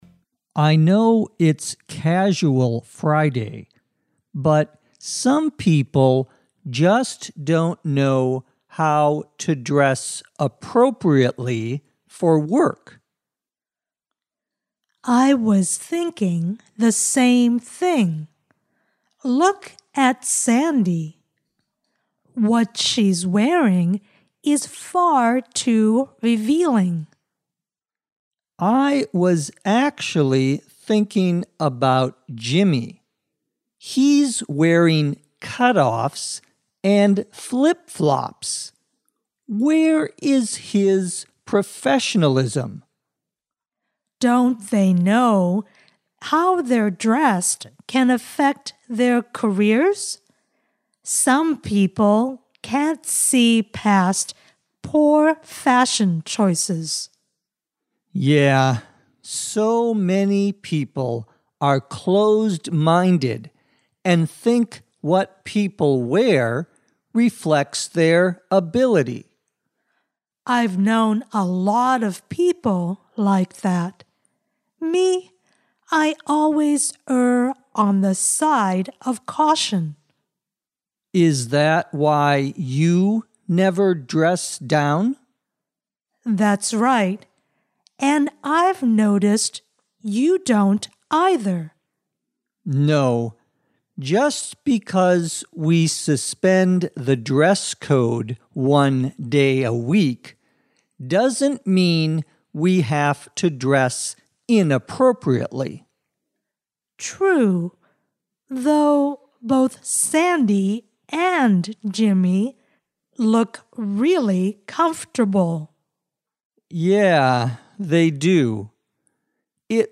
地道美语听力练习:如何着装得体地上班